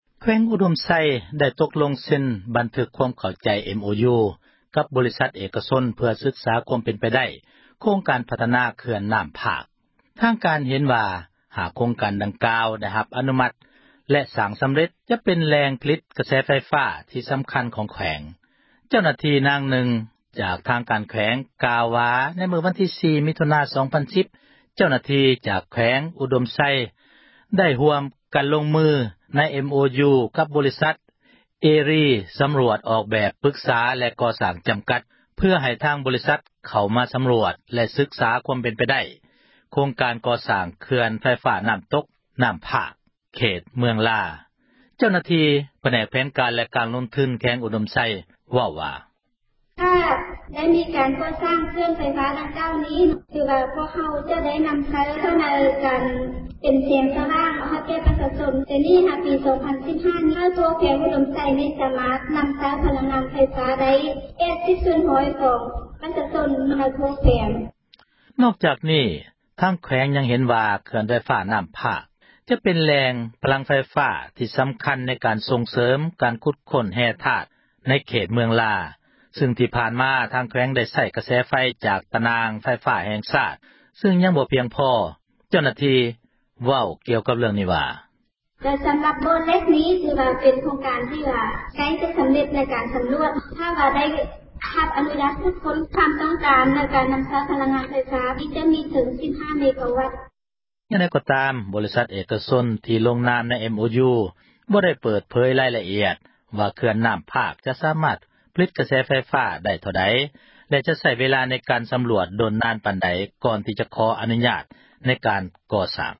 ເຈົ້າໜ້າທີ່ນາງນຶ່ງ ຈາກທາງ ການແຂວງກ່າວວ່າ ໃນມື້ວັນທີ 4 ມິຖຸນາ 2010 ເຈົ້າໜ້າທີ່ຈາກ ແຂວງອຸດົມໄຊ ໄດ້ຮ່ວມກັນ ລົງນາມໃນ MoU ກັບບໍຣິສັດ ເອຣີ ສຳຣວດອອກແບບ ປຶກສາແລະ ກໍ່ສ້າງຈຳກັດ ເພື່ອໃຫ້ທາງ ບໍຣິສັດເຂົ້າ ມາສຳຮວດ ແລະສຶກສາ ຄວາມເປັນໄປໄດ້ ໂຄງການກໍ່ສ້າງ ເຂື່ອນໄຟຟ້າ ນ້ຳຕົກຢູ່ນ້ຳພາກ ເຂດເມືອງລາ.